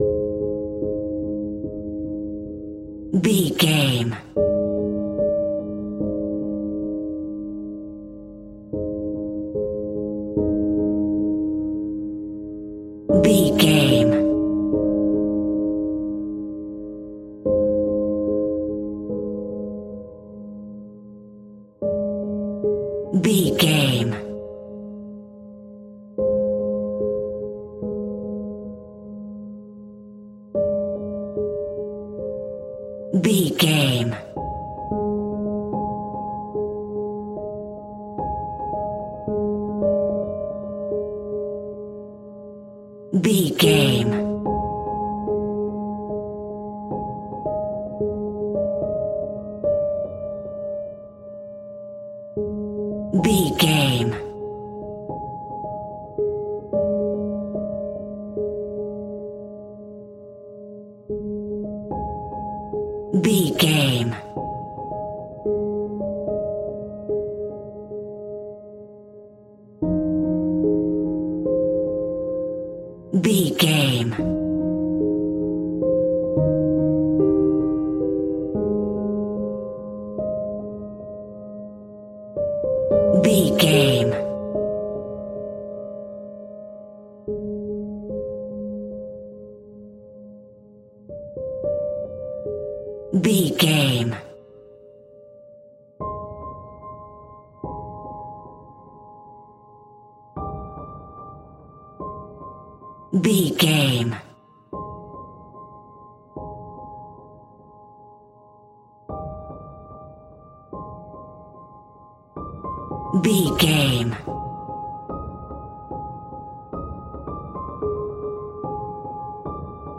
Piano Murder.
Ionian/Major
Slow
tension
ominous
eerie
piano
horror music
horror piano